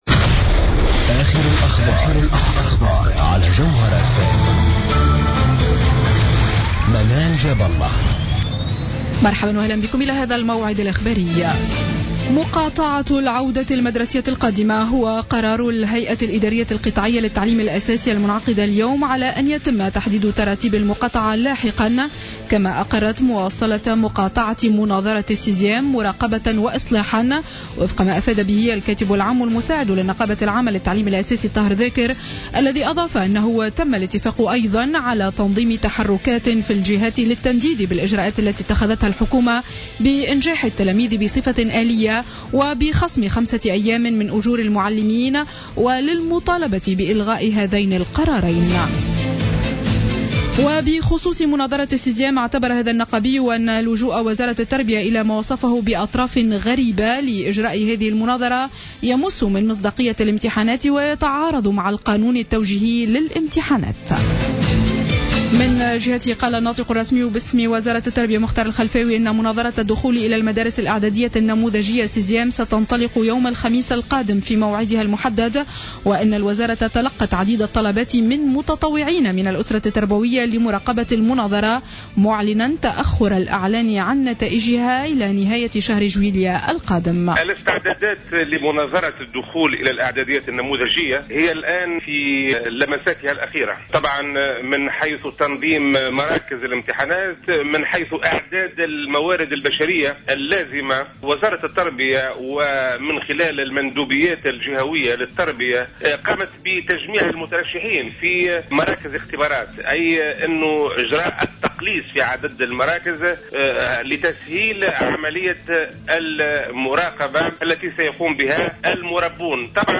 نشرة أخبار السابعة مساء ليوم الأحد 14 جوان 2015